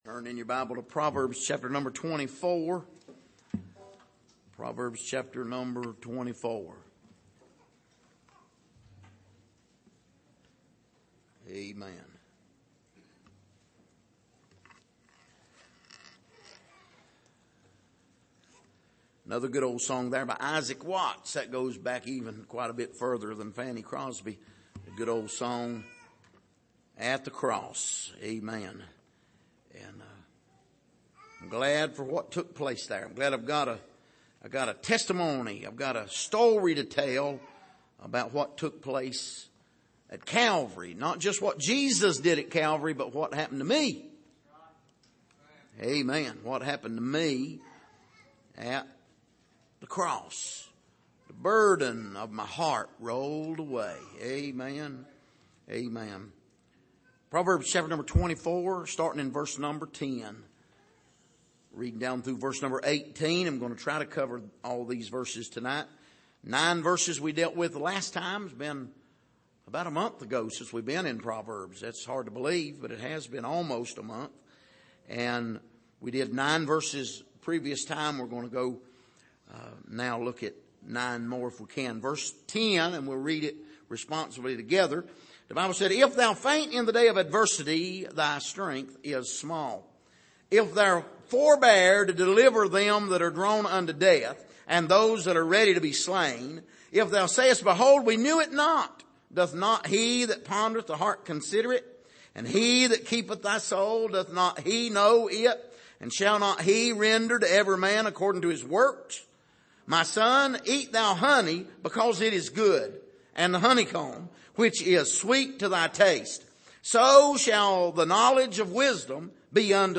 Passage: Proverbs 24:10-18 Service: Sunday Evening